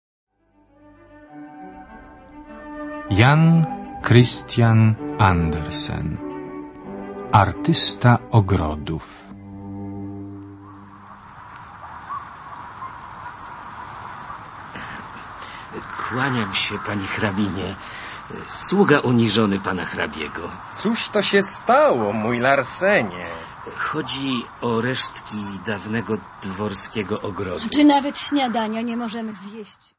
Children's Stories by Hans Christian Anderson read in Polish with Accompanying Music.